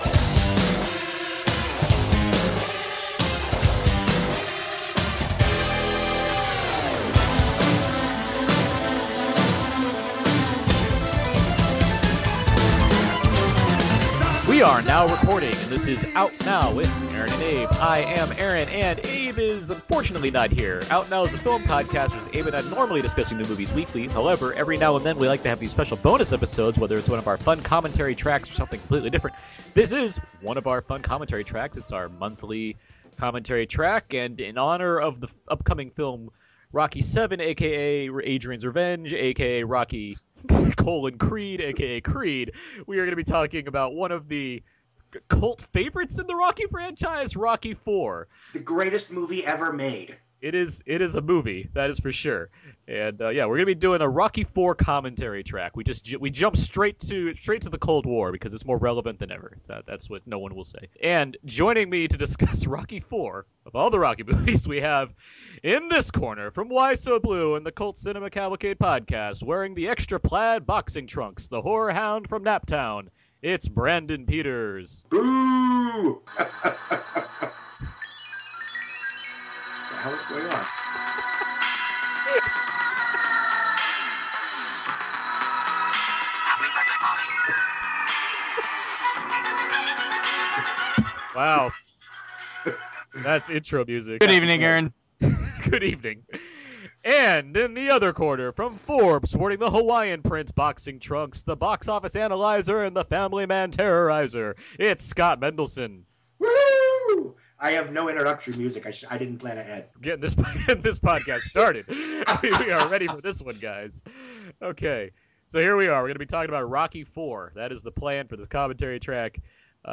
Listen in to a fact and fun-filled commentary track.